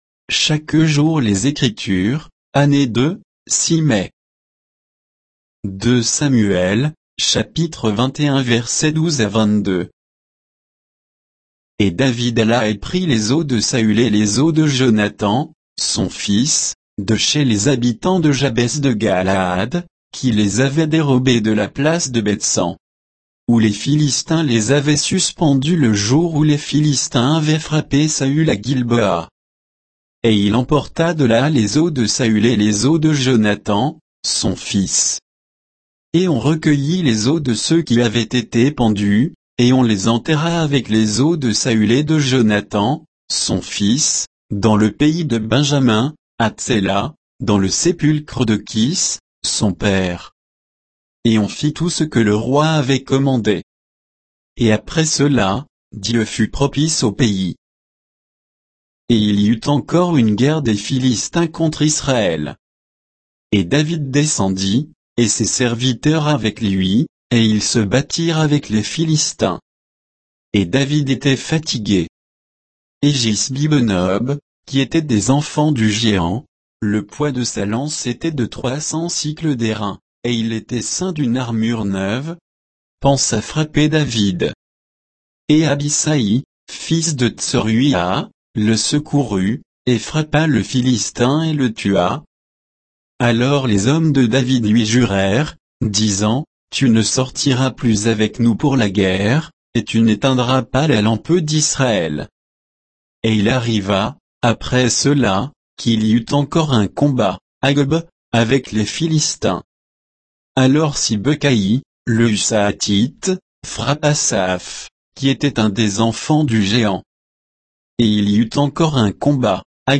Méditation quoditienne de Chaque jour les Écritures sur 2 Samuel 21